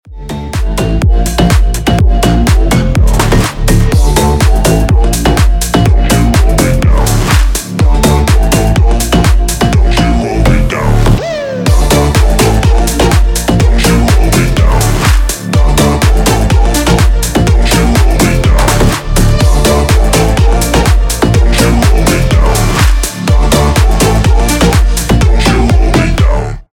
• Качество: 320, Stereo
мужской голос
Electronic
EDM
динамичные
Стиль: house